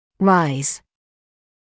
[raɪz][райз]подниматься, повышаться; подъём, повышение